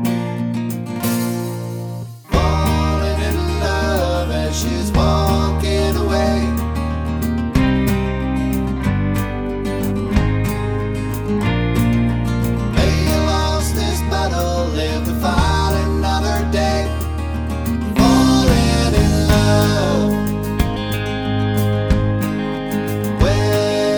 no Backing Vocals Country (Male) 3:40 Buy £1.50